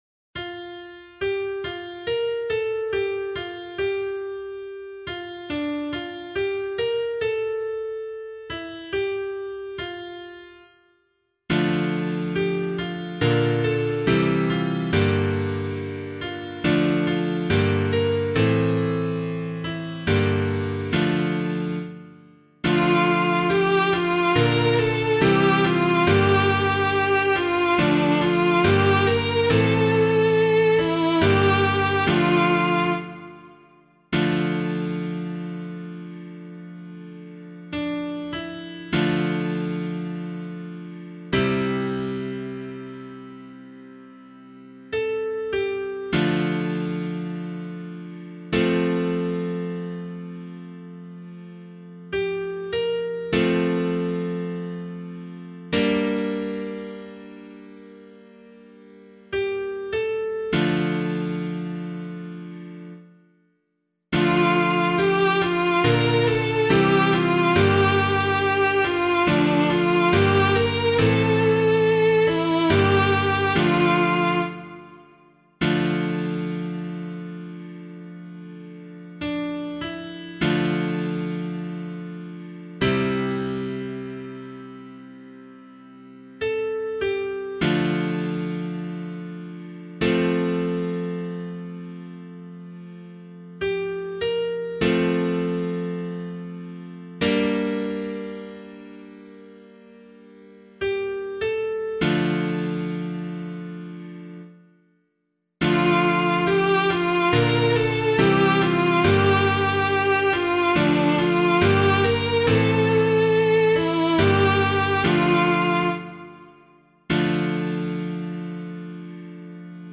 002 Advent 2 Psalm A [LiturgyShare 1 - Oz] - piano.mp3